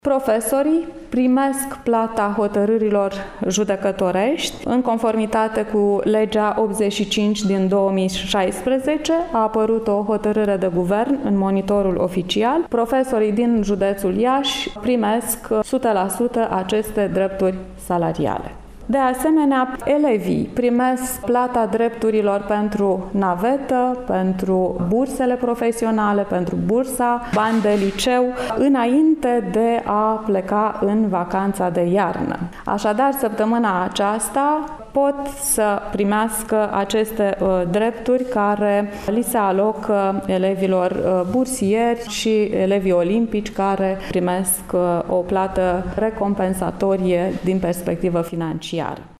Anunţul a fost făcut de  Şefa Inspectoratului Şcolar General Iaşi, Genoveva Farcaș: